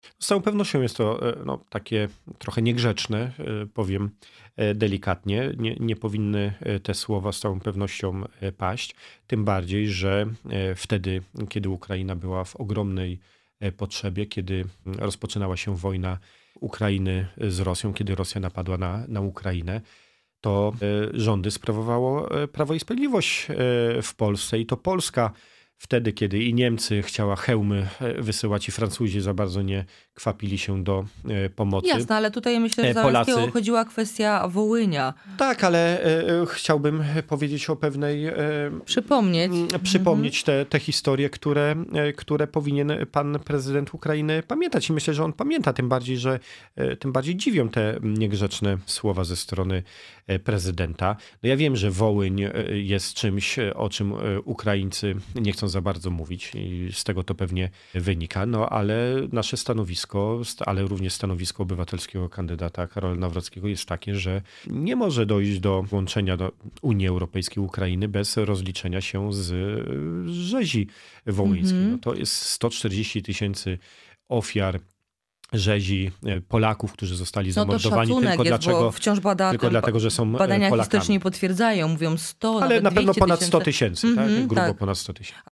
Te tematy poruszyliśmy z „Porannym Gościem” – posłem na Sejm Pawłem Hreniakiem, szefem struktur PiS w okręgu wrocławskim.